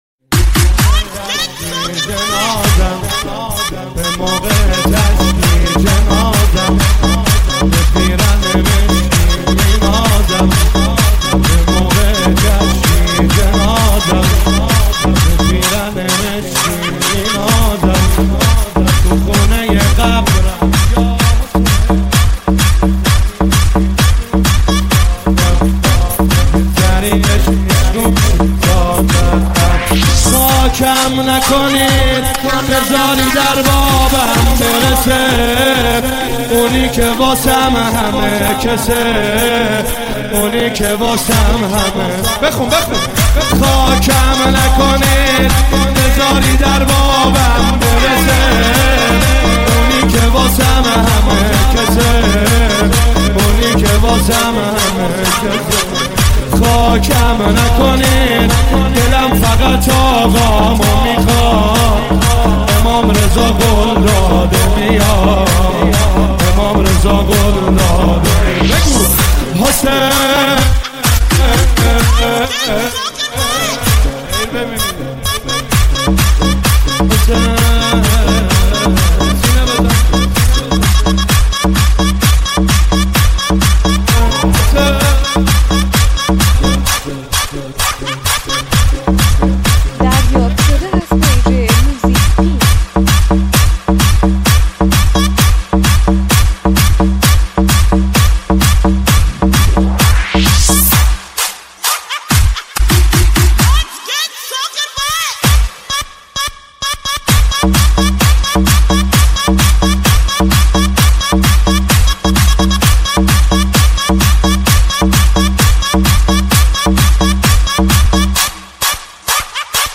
ریمیکس بیس دار نوحه
برچسب هاپرطرفدار ، تک آهنگ ، نوحه